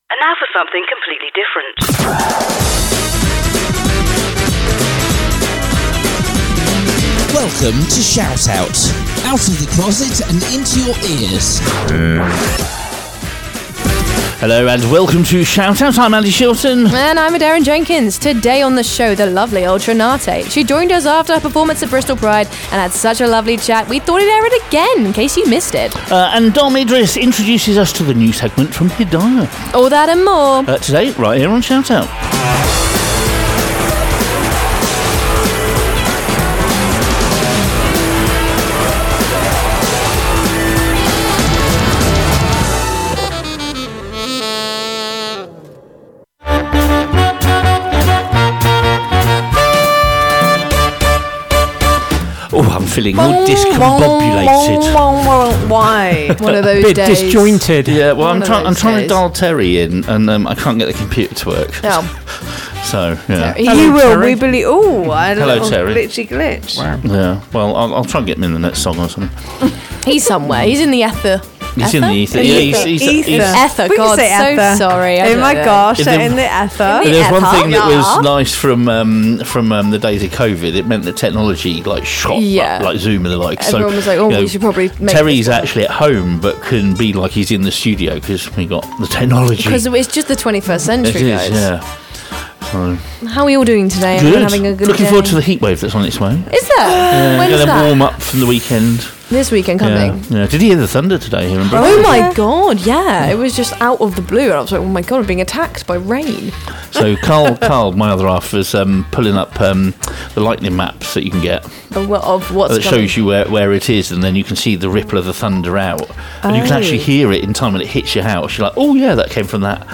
Broadcasting a brand new show every week, ShoutOut is a magazine show aimed at the LGBT community and their friends. We cover everything from serious to the stupid with live guests, events and news listing and special features.